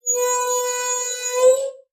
tr_dieseltruck_brake_03_hpx
Diesel truck air brakes release and screech. Vehicles, Truck Brake, Release